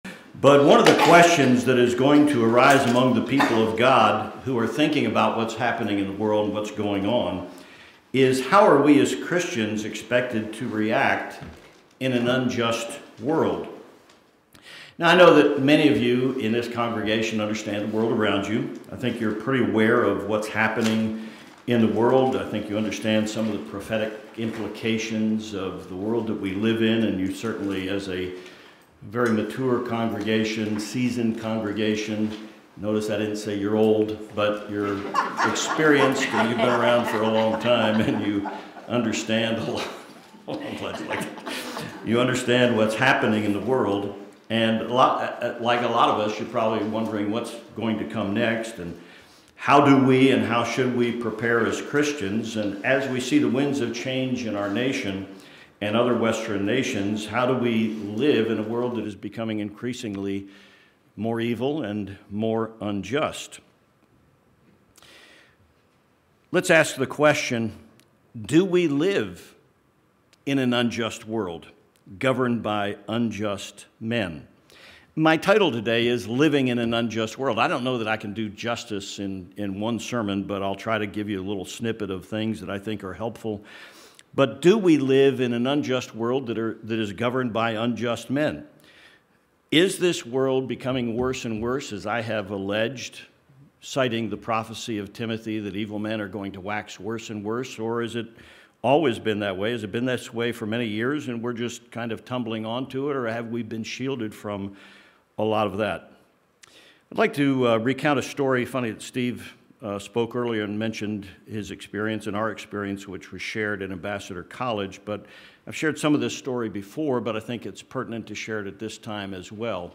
Given in El Paso, TX Tucson, AZ